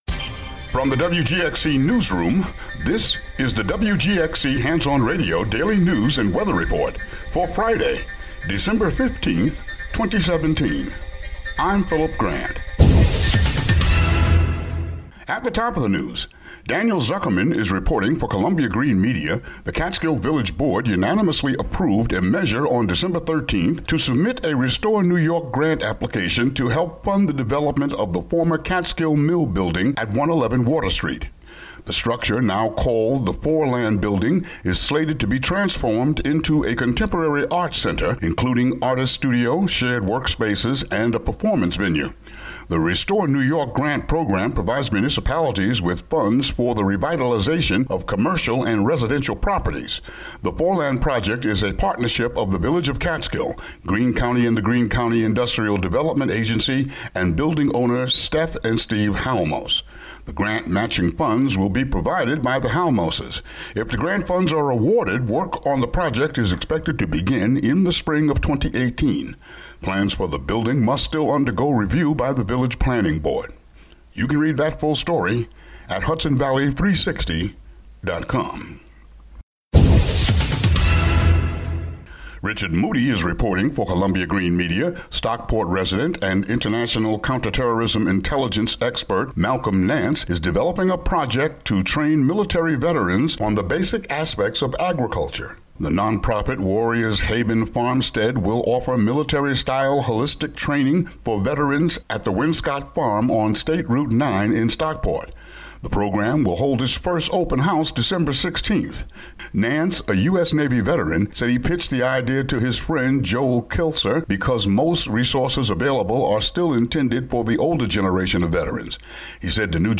Daily local news for Fri., Dec. 15.